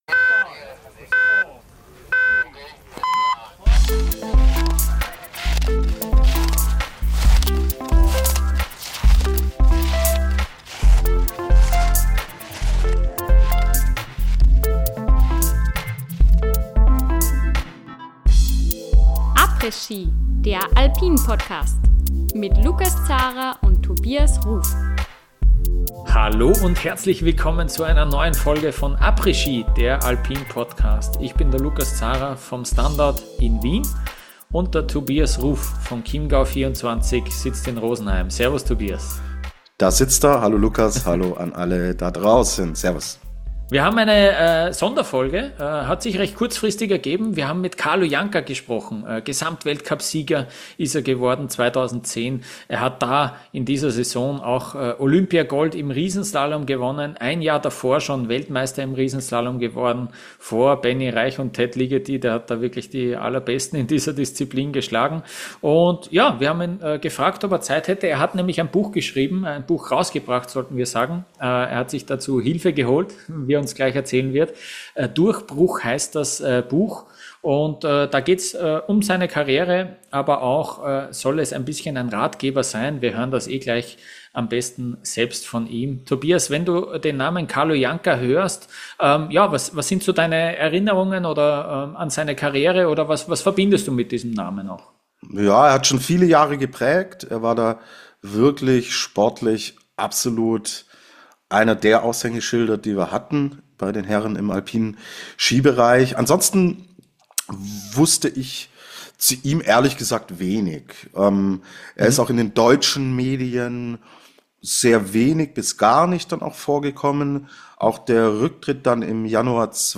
Durchbruch! Interview mit Carlo Janka ~ Après Ski - Der Alpin-Podcast Podcast